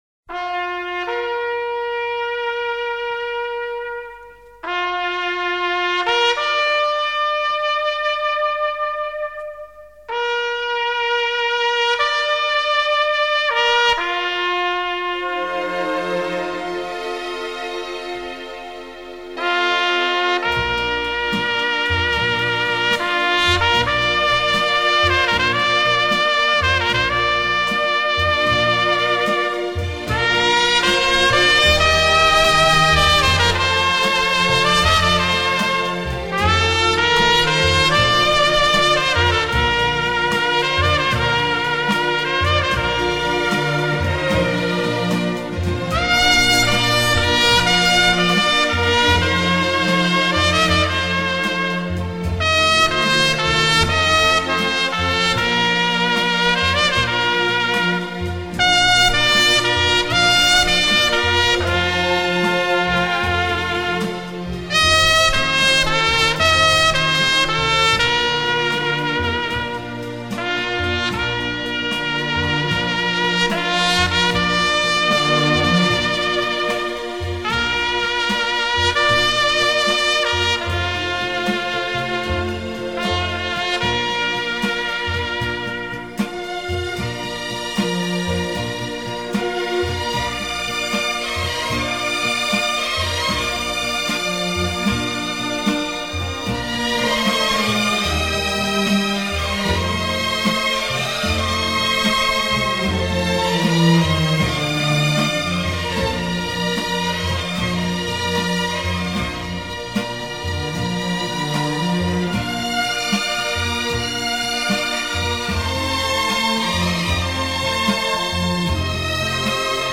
Genre:Instrumental,Easy Listening